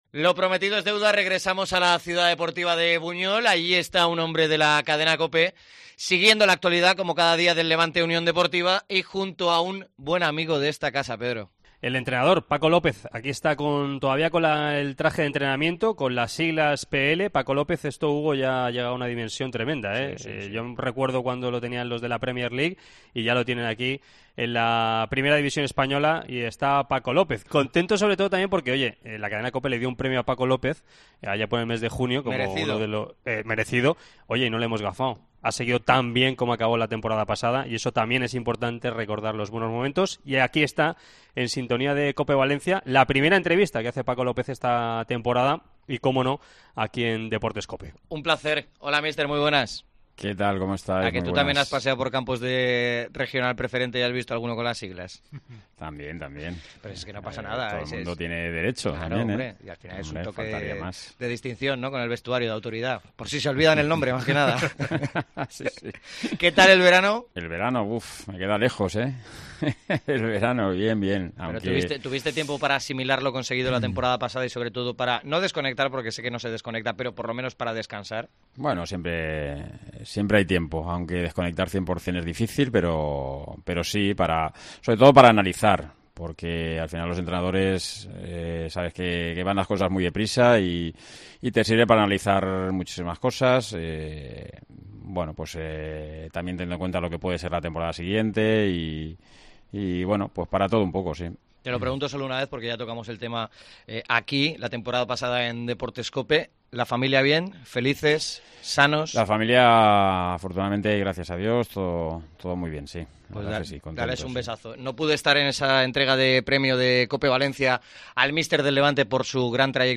El entrenador del Levante concede la primera entrevista de la temporada en COPE VALENCIA.